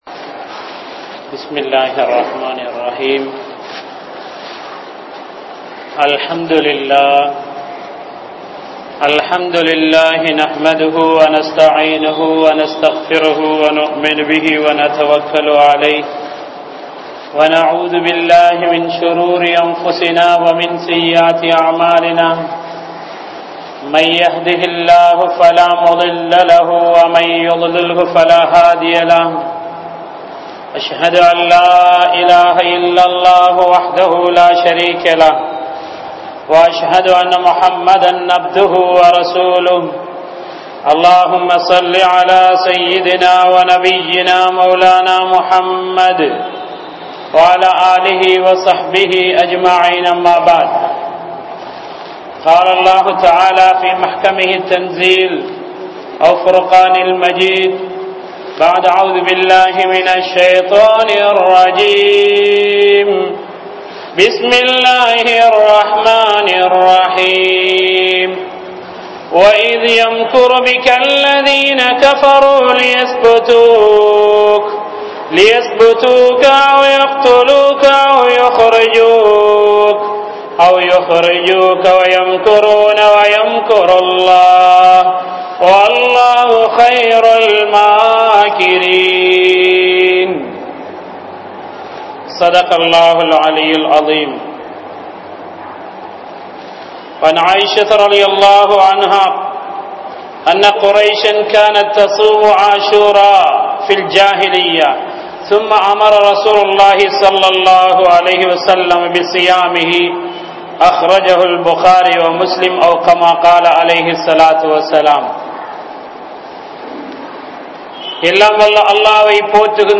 Thiyaahathin Mudivu Vettri (தியாகத்தின் முடிவு வெற்றி) | Audio Bayans | All Ceylon Muslim Youth Community | Addalaichenai
Thaqwa Jumua Masjith